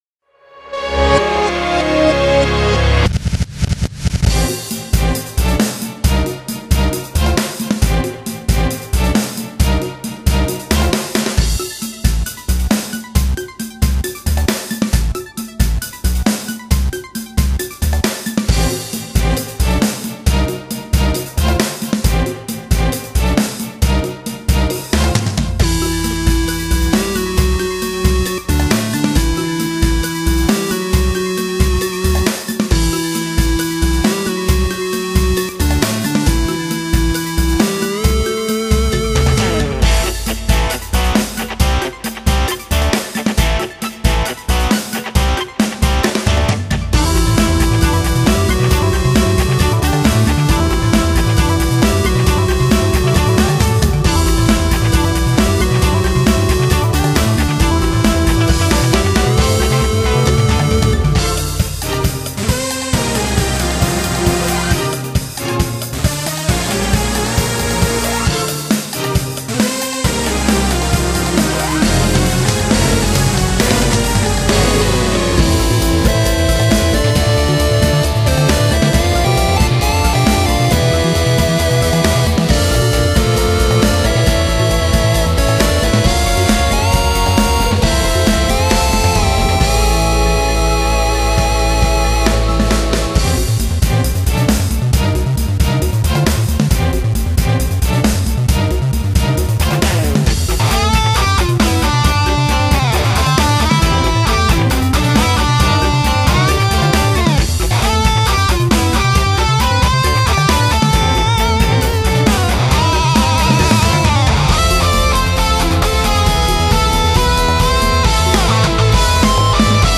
ファイルは「YAMAHA MU1000EX」「Roland SC-8850、INTEGRA-7」で制作したものを